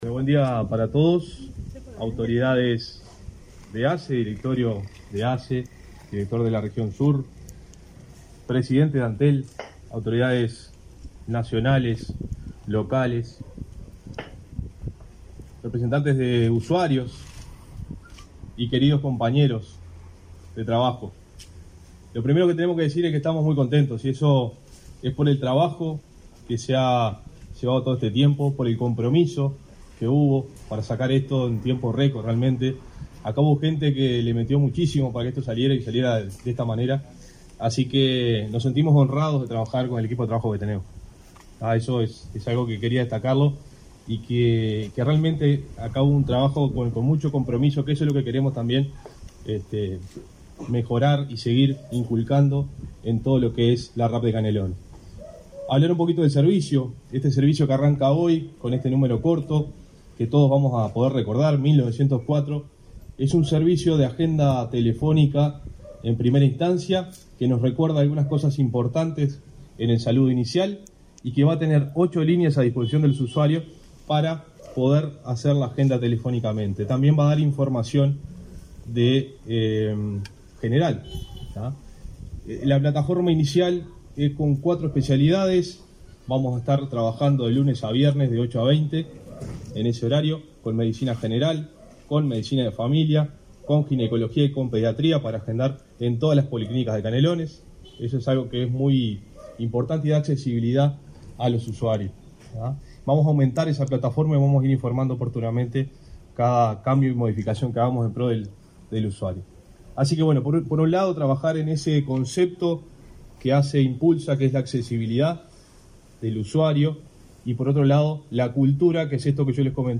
Conferencia de prensa por la inauguración del servicio telefónico de la Red de Atención Primaria de Canelones